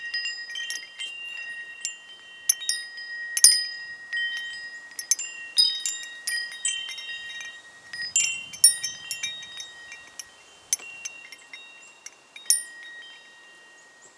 windchime1-optimized.wav